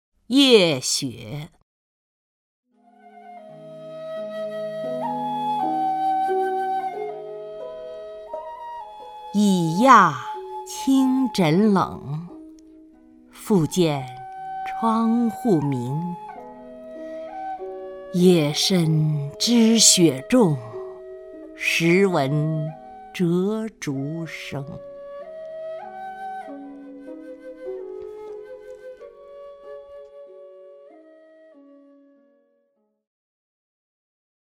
曹雷朗诵：《夜雪》(（唐）白居易) (右击另存下载) 已讶衾枕冷， 复见窗户明。